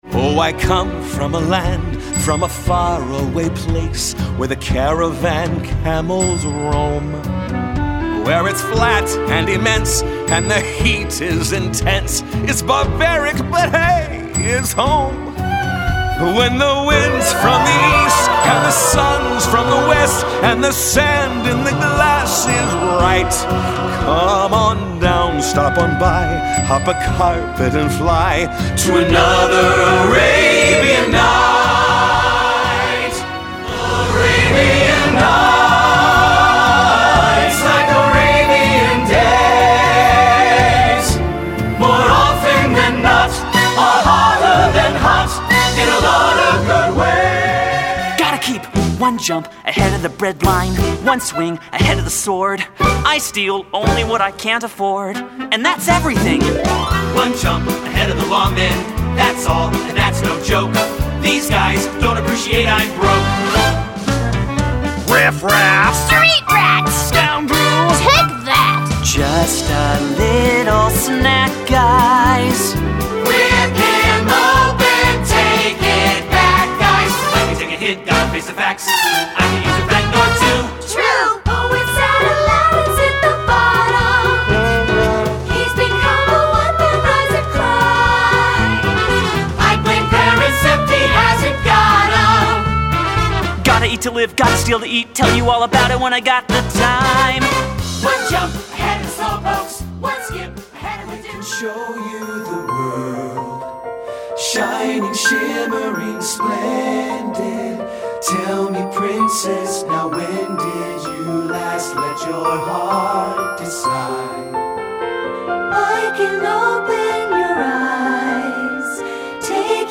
Choral Movie/TV/Broadway
Voicing
SATB